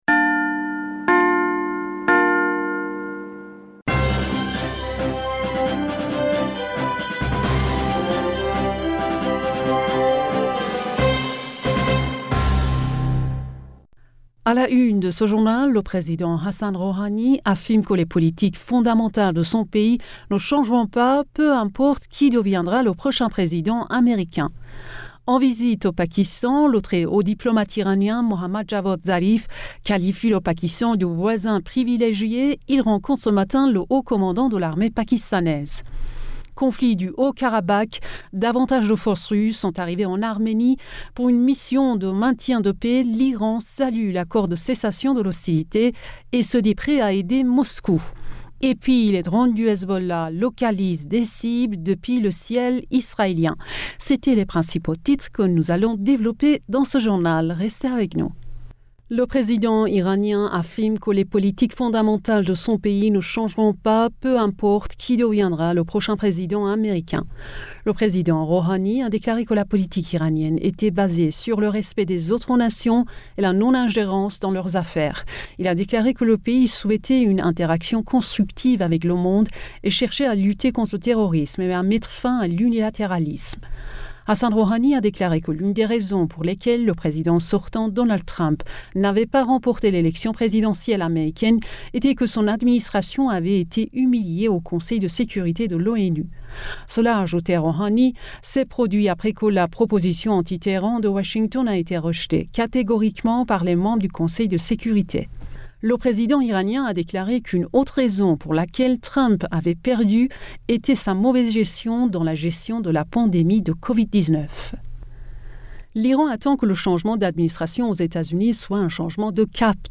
Bulletin d'informationd u 11 November 2020